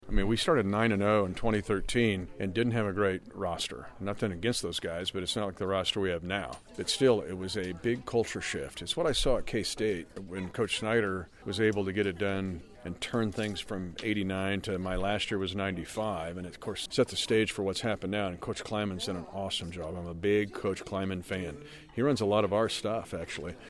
Voice of the Kansas City Chiefs Mitch Holthus delivered the keynote speech at Tuesday’s ceremony, recalling how head coach Andy Reid’s leadership inspired a winning culture after the organization had sunk to its lowest point in franchise history.